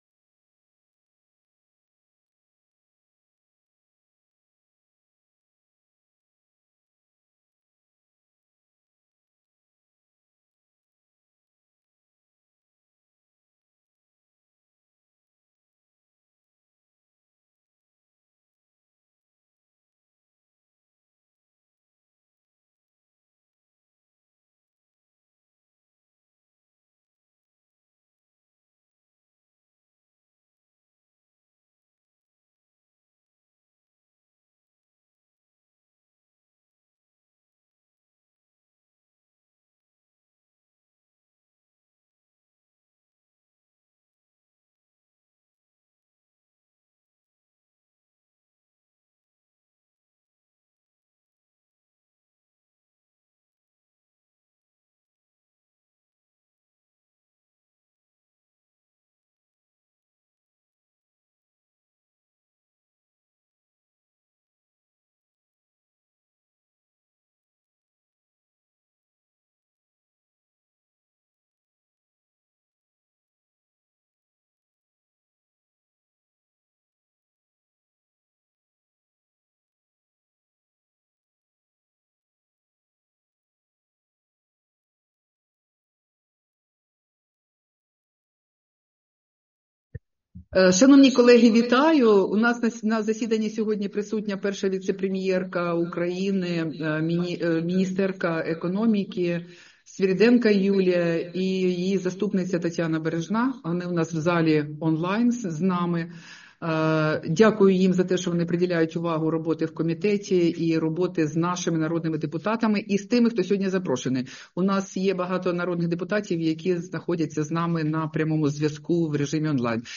Засідання Комітету від 13 листопада 2024 року